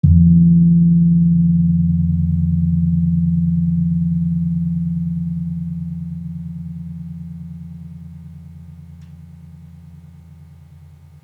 Gong-F2-f.wav